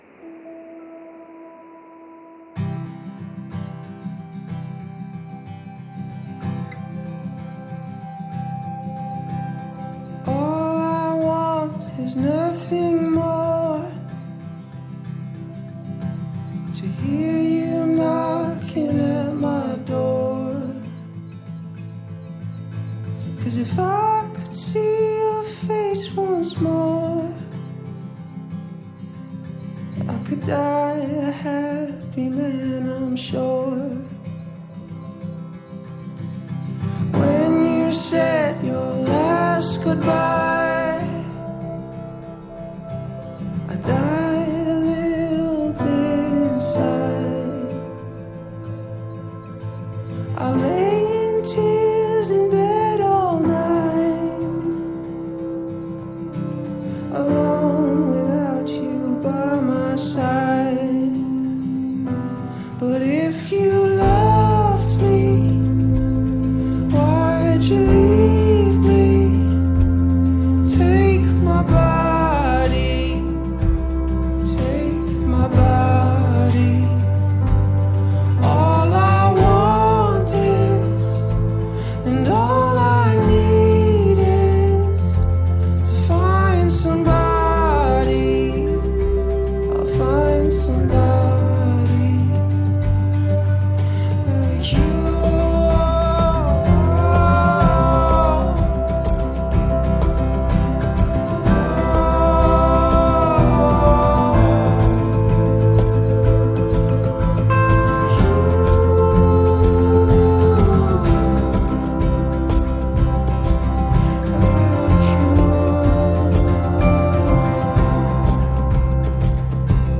Жанр: Classic Rock